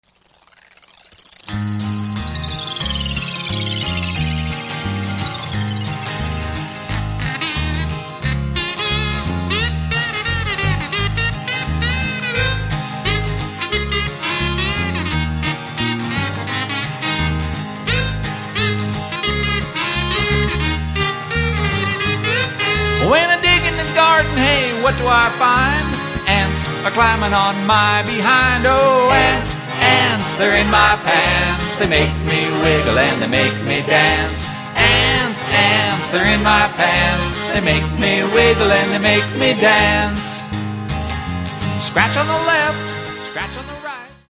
--funny kids' music